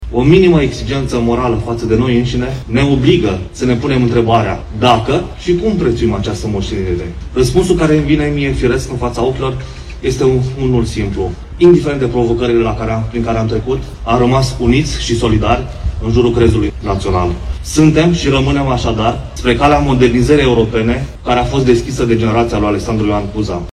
Astăzi, de Ziua Unirii Principatelor Române, oficialitățile locale și județene sucevene au inaugurat bustul lui ALEXANDRU IOAN CUZA, amplasat în Piața Drapelelor din centrul municipiului reședință.
Prefectul ALEXANDRU MOLDOVAN a amintit etapele istorice din perioda Micii Uniri, menționând că perioada respectivă “s-a caracterizat prin dragostea elitelor față de popor și țară”.